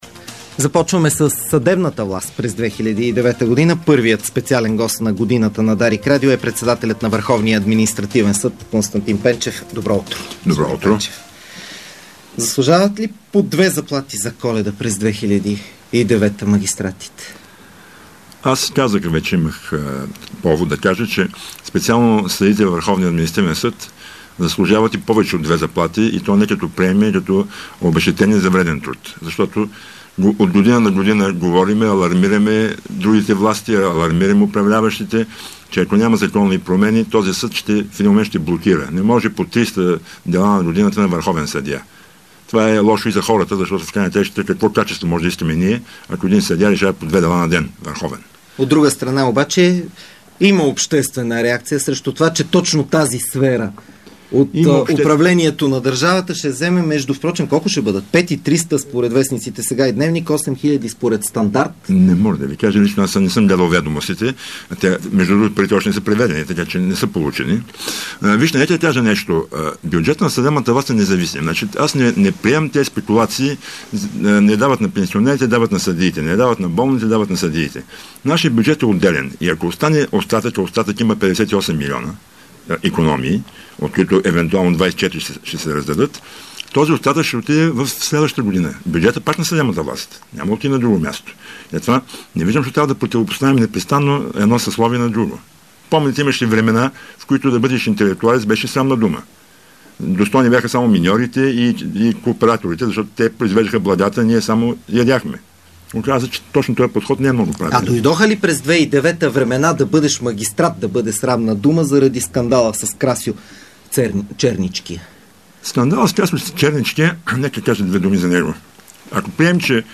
Интервю с Константин Пенчев